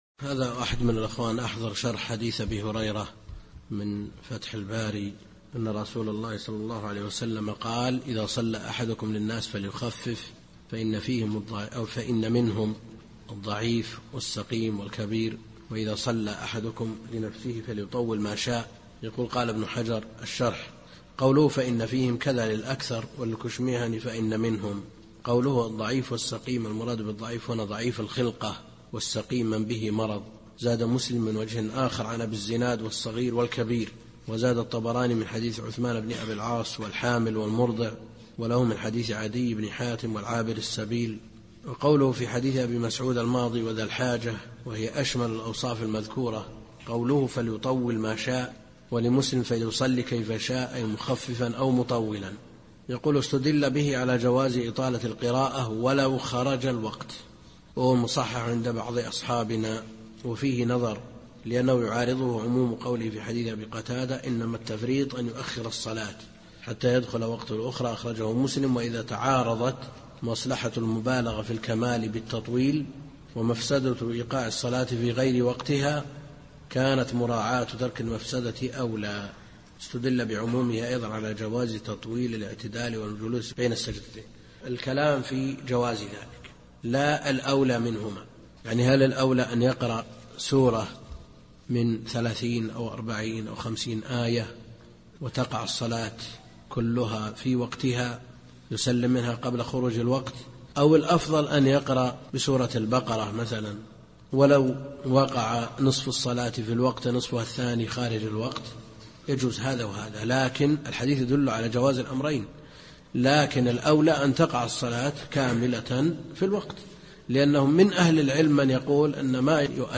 الدرس الثلاثون من دروس شرح بلوغ المرام كتاب الصلاة للشيخ عبد الكريم الخضير